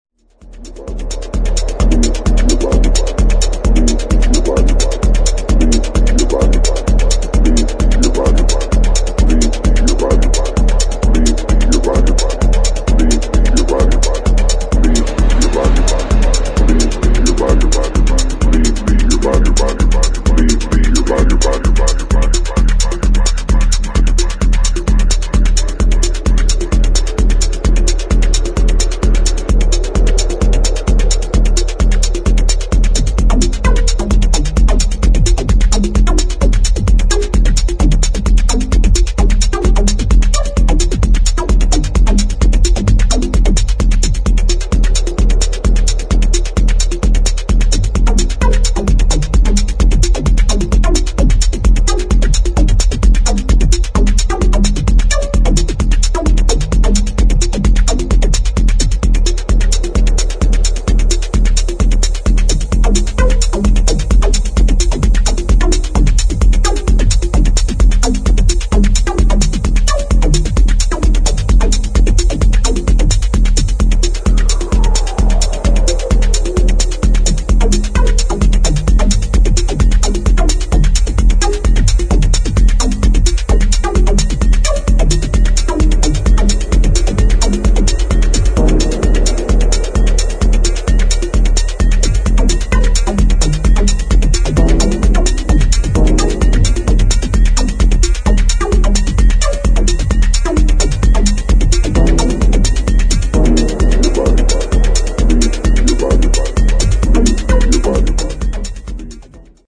[ TECH HOUSE ]